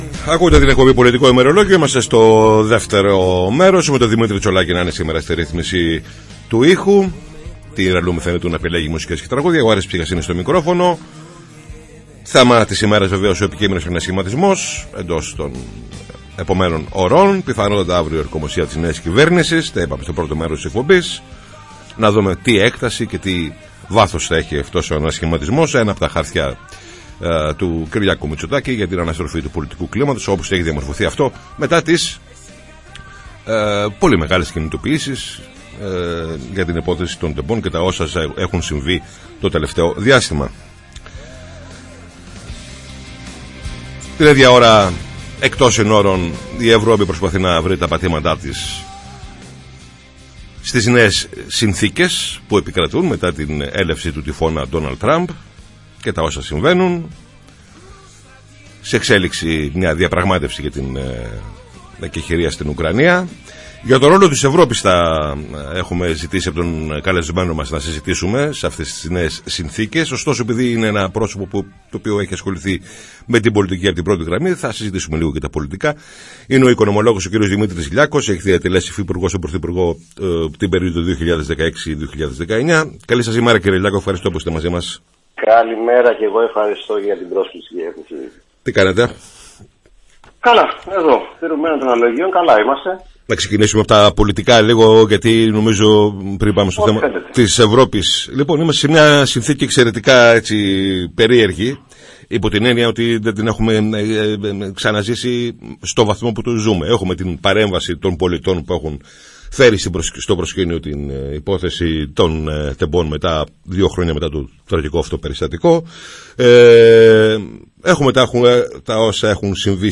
Καλεσμένος ο Δημήτρης Λιάκος | 17.03.2025